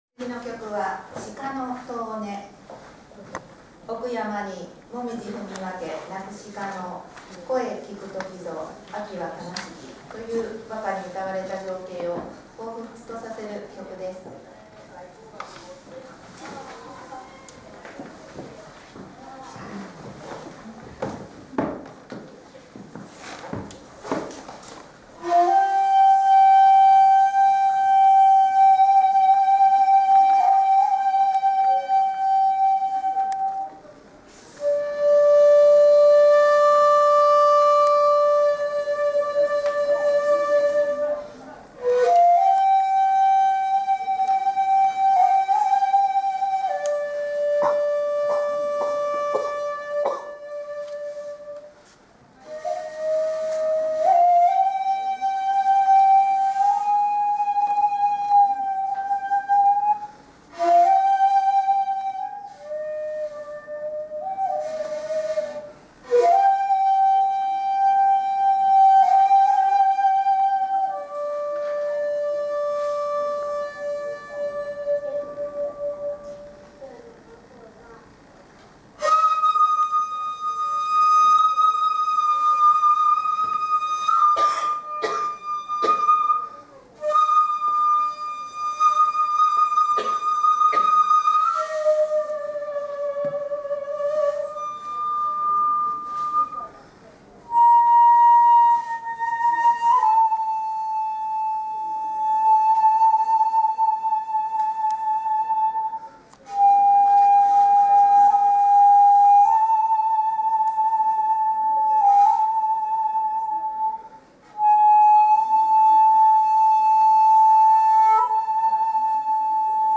平成から令和になっての初めてのコンサートでした。
会場は築200年は経つ泉佐野市指定文化財の新川家という所です。
すべて木造のたいへん響きが良く羽毛が琴糸の上に落ちる音でも聞こえるくらいです。
むら息、風息も多用しますので一般的に金属的な音になりやすい地塗り管ではこの「鹿の遠音」の良さを生かしにくいと思います。